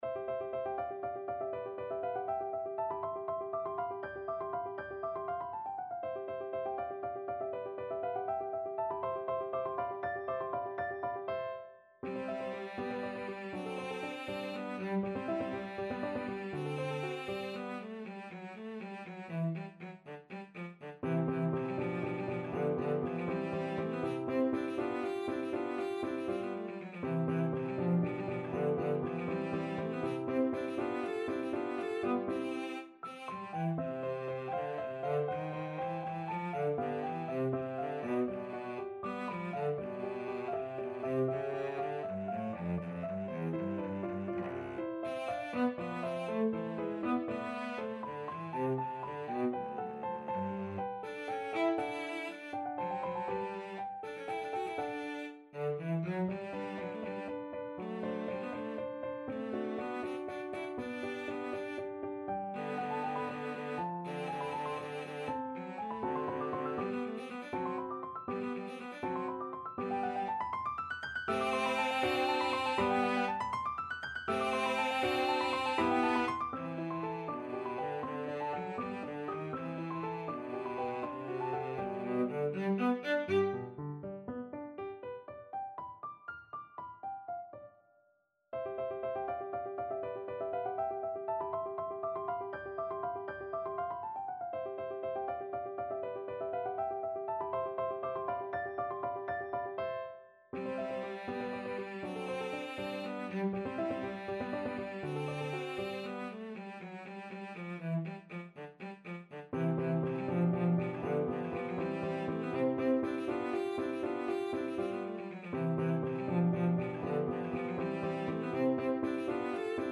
6/8 (View more 6/8 Music)
~ = 100 Allegro (View more music marked Allegro)
Classical (View more Classical Cello Music)